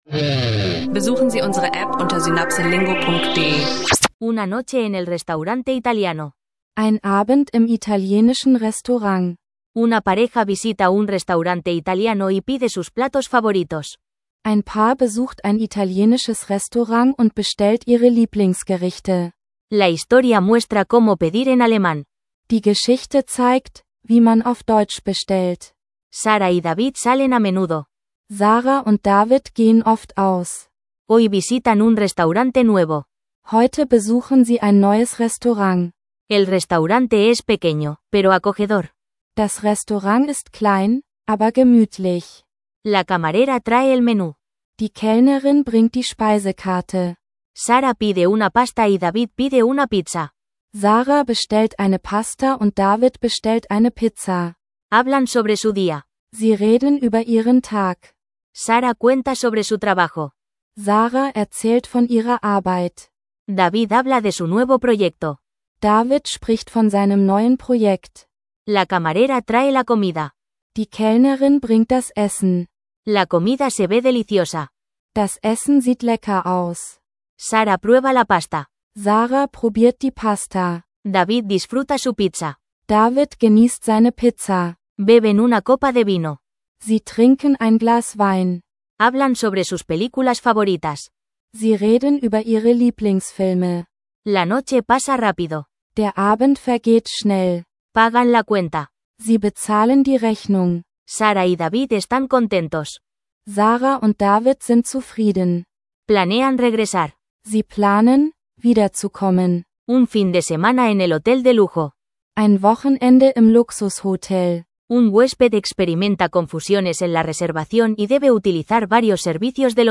Erlebe, wie ein Paar in einem italienischen Restaurant bestellt und einfache Konversationen auf Spanisch führt. Ideal für Anfänger, die Spanisch im Alltag spielerisch erlernen möchten.